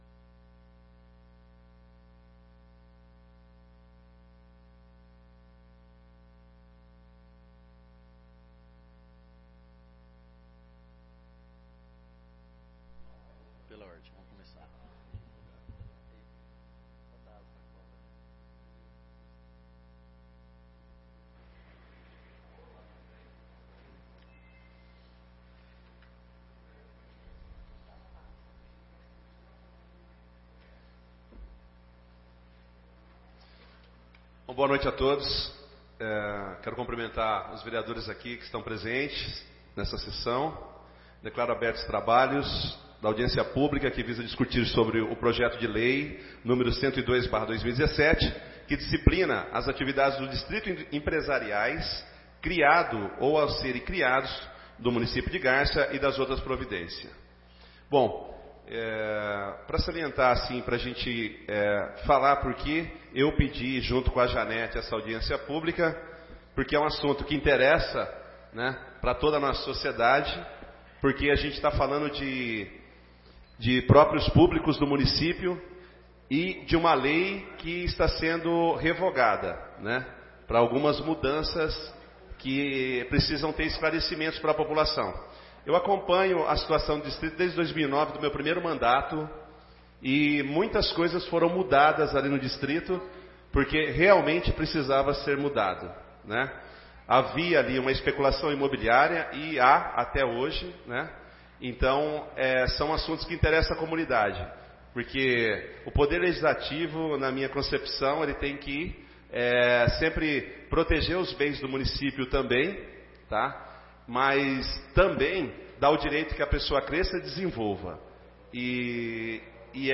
09/03/2018 - Audiência Pública sobre os Distritos Empresariais